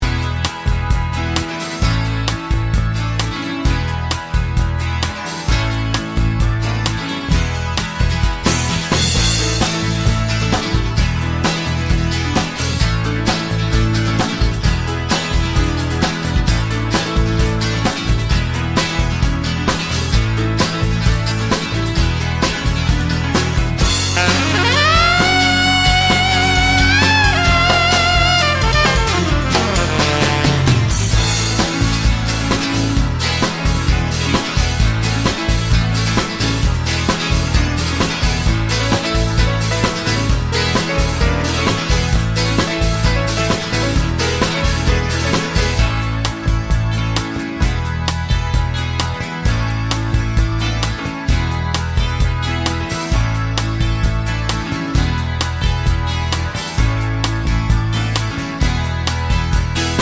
no Backing Vocals Irish 3:47 Buy £1.50